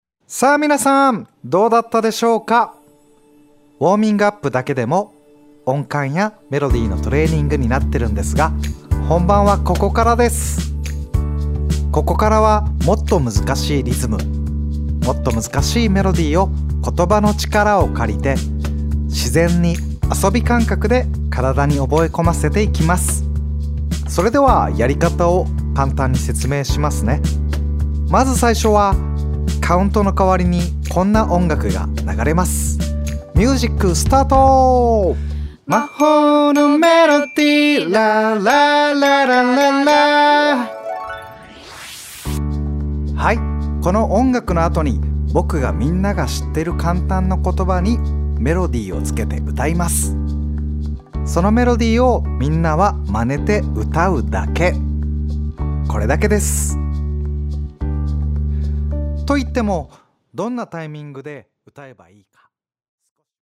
4分音符と簡単な音程の組み合わせ、インストラクターが歌うメロディのマネをするだけです。
ロボットの声とインストラクターの後に続いて見本を演奏。
インストラクターが、言葉と同時にメロディを歌います。
同じフレーズをテンポを変えて演奏します。